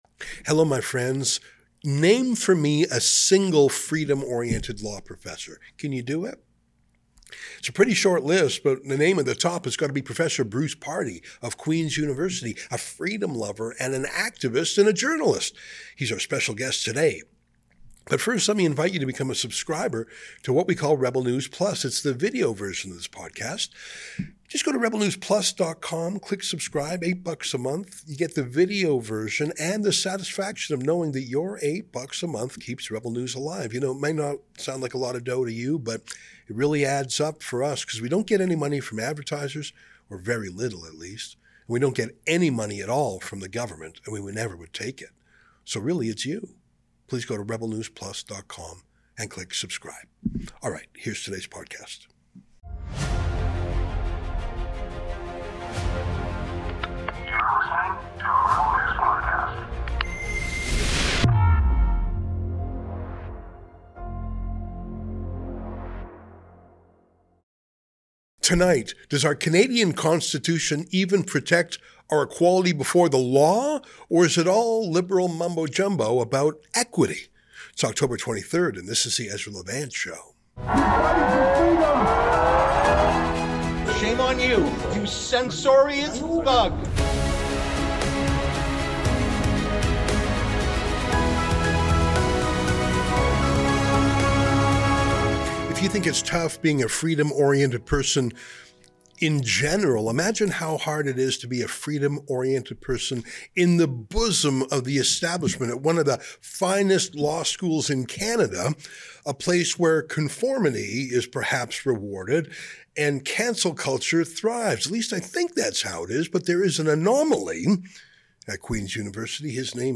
The path we're going down is intolerable and untenable: an interview